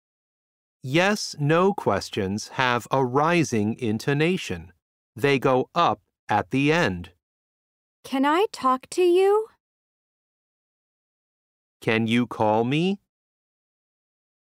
SO1- Unit 5- Lesson 4 (Intonation).mp3